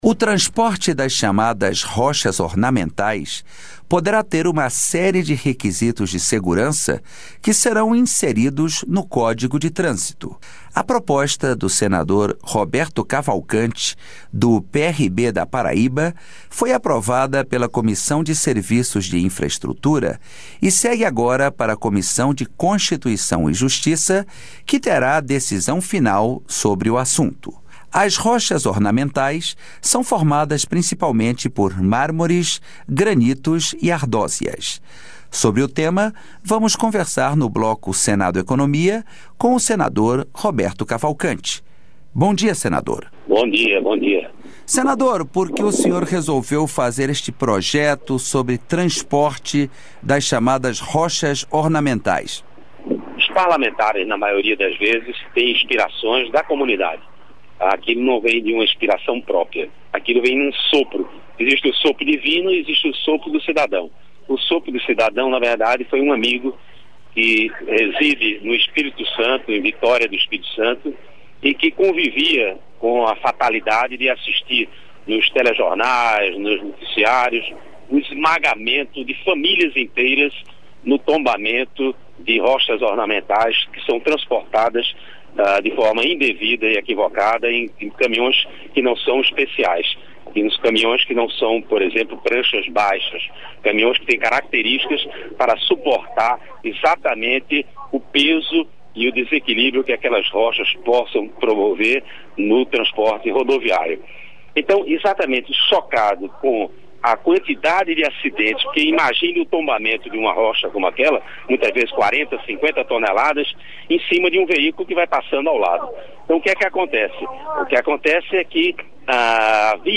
Entrevista com o senador Roberto Cavalcanti (PRB-PB).